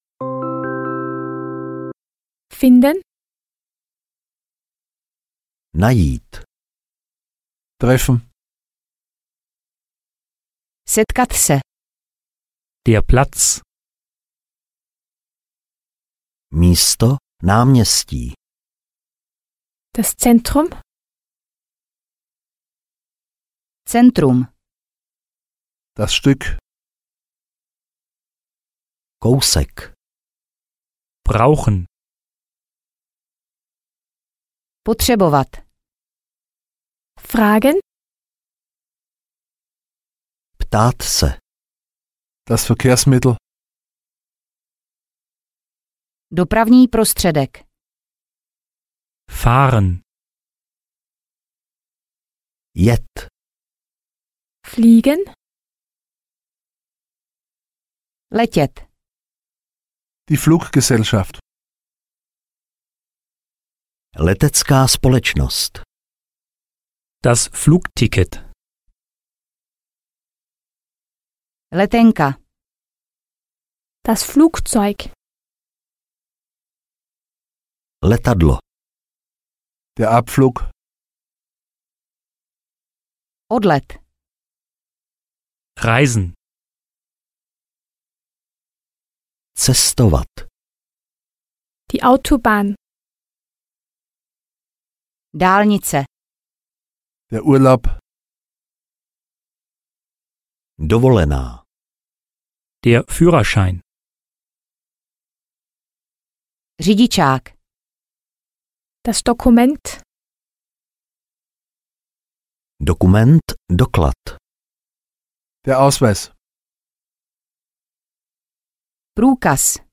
Němčina do ucha audiokniha
Ukázka z knihy